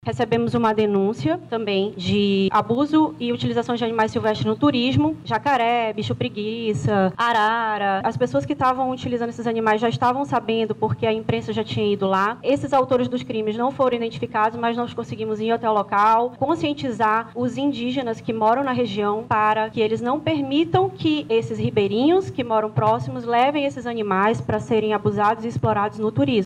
Já na Operação Anhangá, de combate a exploração e abuso de animais silvestres, a delegada explica que a Polícia não conseguiu prender os autores das ações criminosas.